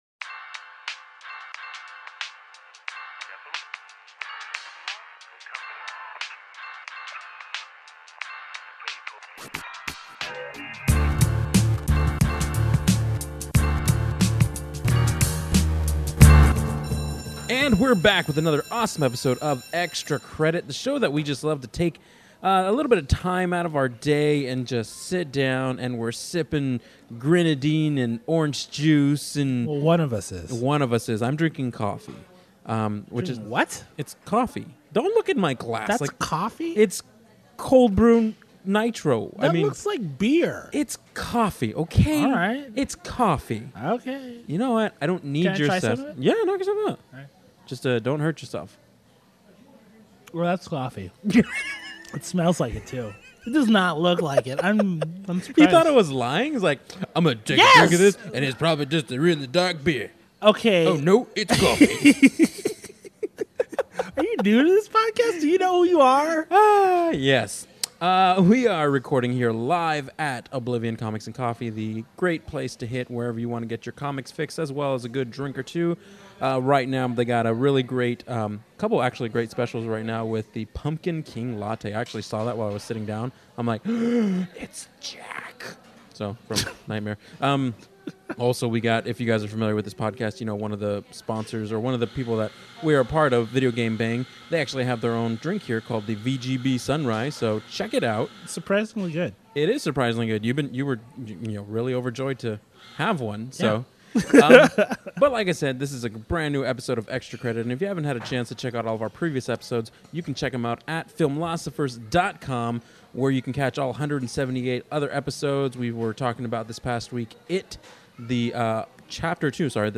Back at the Honeycomb Hideout, a.k.a. the incredible Oblivion Comics & Coffee, the Perilous Pair pull up a chair, grab their coffees and reveal some of the more disturbing parts of the film, with a bit of spoilery fun.